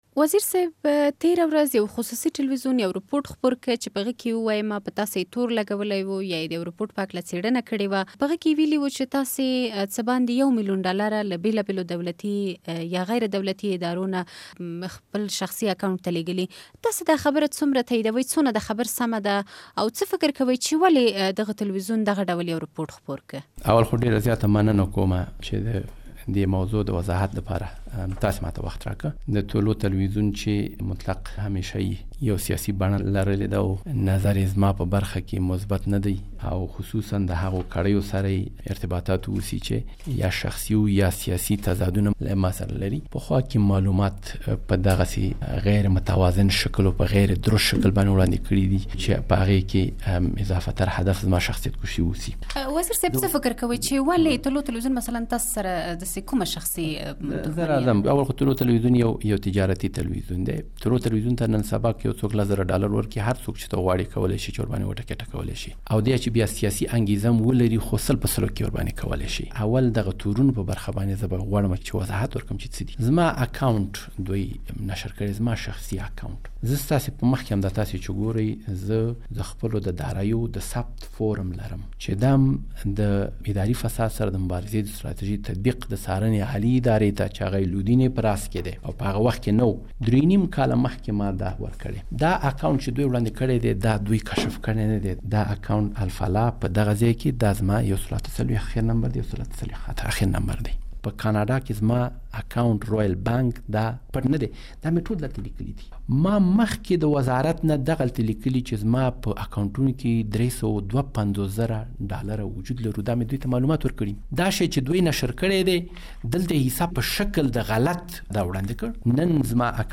له ډاکټر عمر زاخېلوال سره مرکه